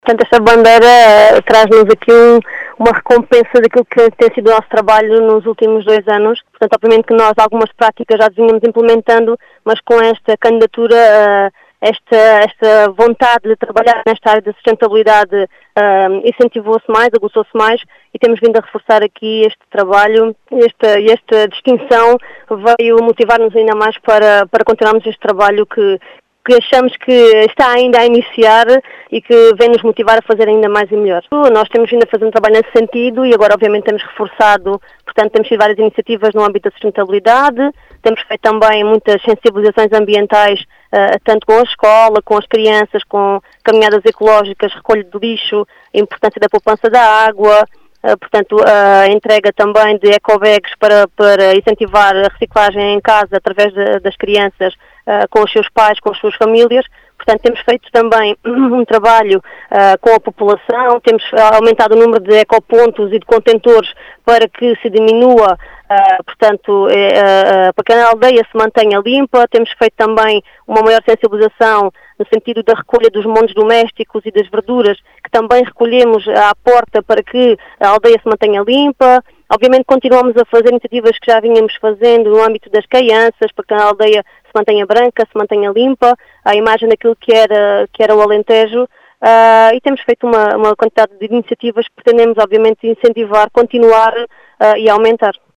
Maria João Brissos, presidente da junta de freguesia de Baleizão fala numa “recompensa” pelo trabalho desenvolvido, acrescida de uma maior motivação.